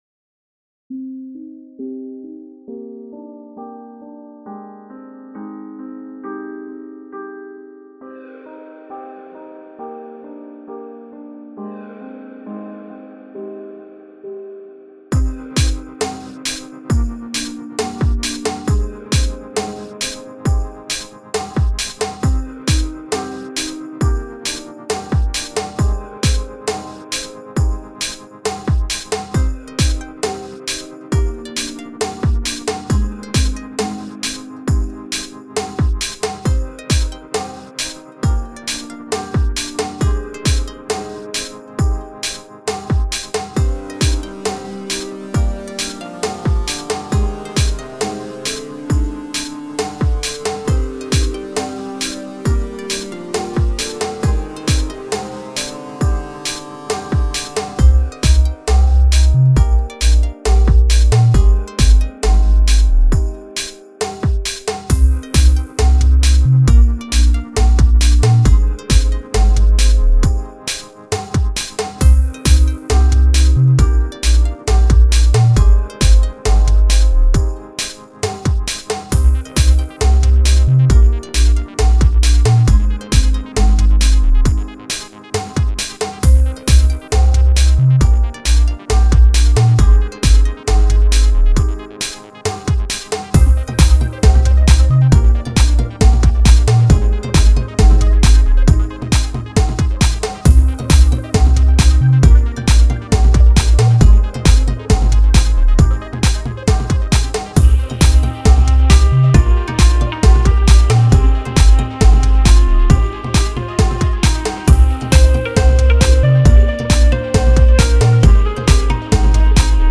dance/electronic
Created in Jeskola Buzz.
Techno
Pop